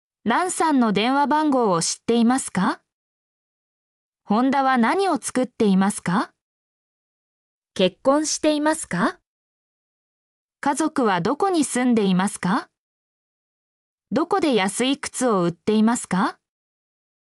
mp3-output-ttsfreedotcom-32_wN2l8rdB.mp3